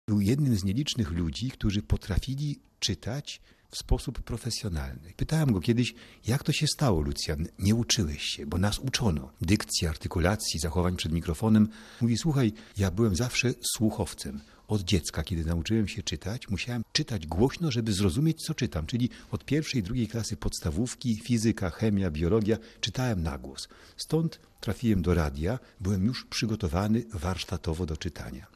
Jego ciepły, charakterystyczny głos widzowie znają z ponad 20 tysięcy filmów.
Po odebraniu wyróżnienia Lucjan Szołajski wspominał swój pierwszy występ na antenie: